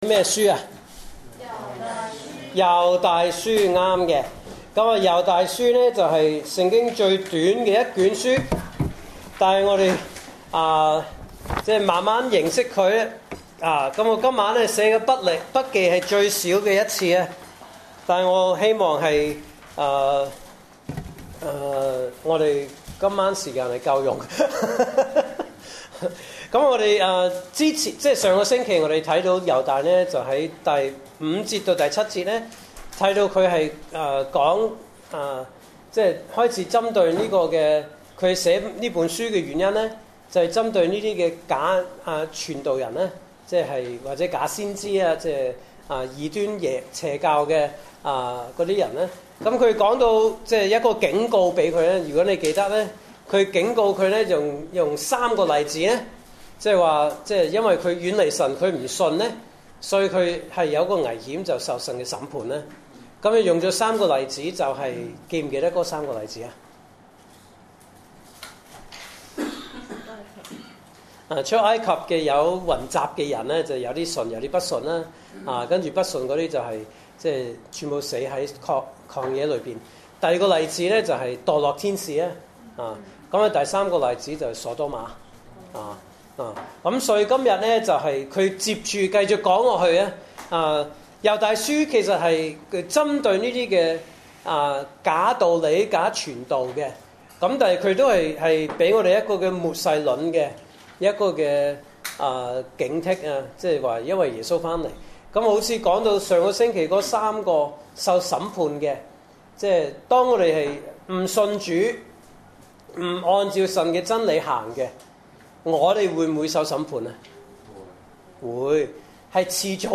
來自講道系列 "查經班：猶大書"